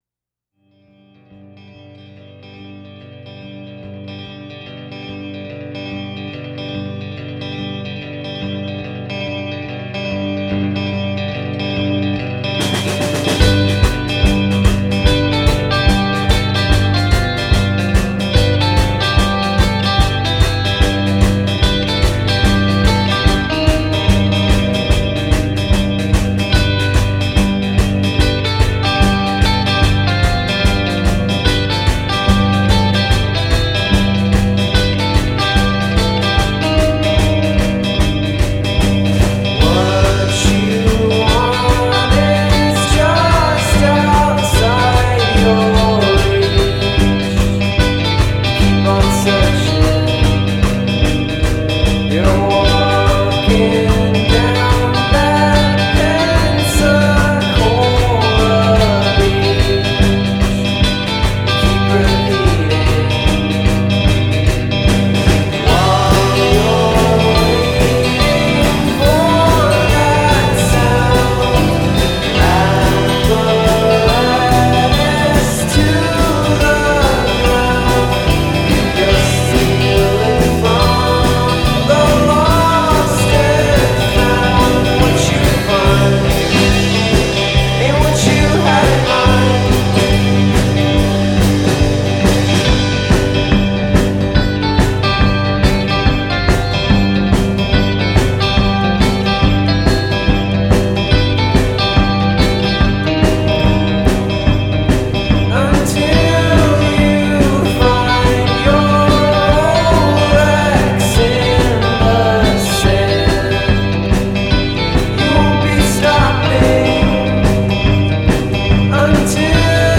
hazy dreamy imagery